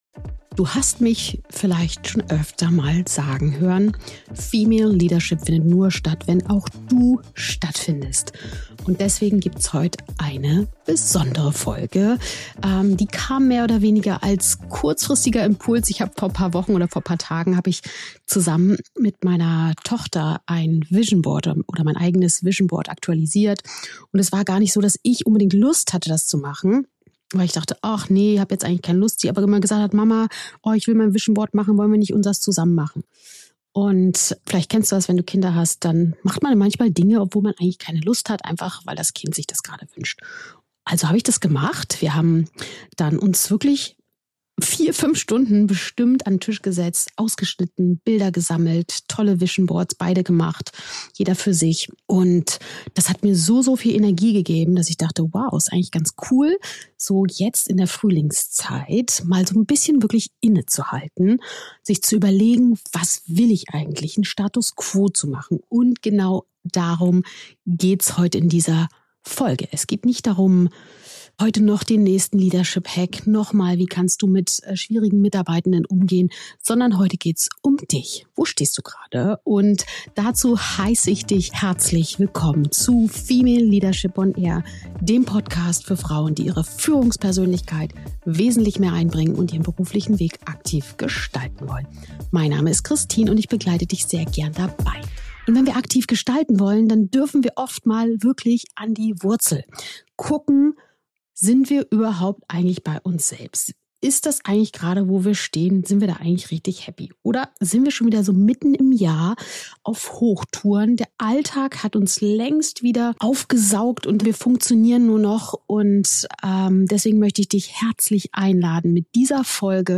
Kennst du das Gefühl, nur noch zu funktionieren, statt wirklich bei dir zu sein? In dieser besonderen Solo-Folge von Female Leadership On Air geht es ausnahmsweise nicht um den nächsten Leadership-Hack oder um noch mehr Optimierung im Außen.